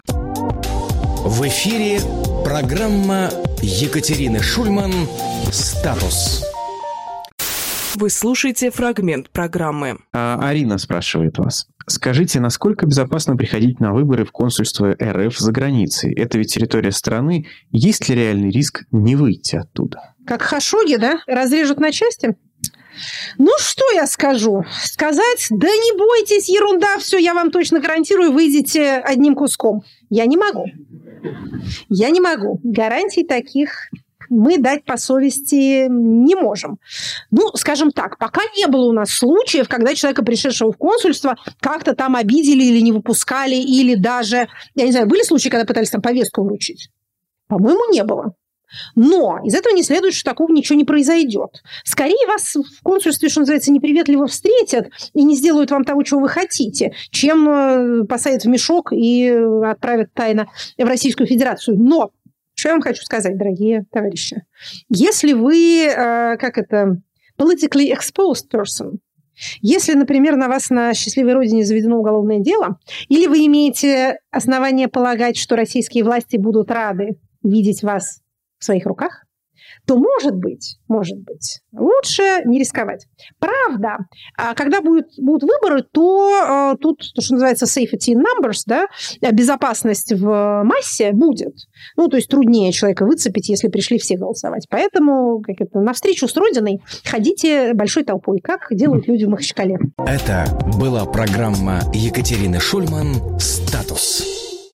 Екатерина Шульманполитолог
Максим Курниковглавный редактор «Эха», журналист
Фрагмент эфира от 31.10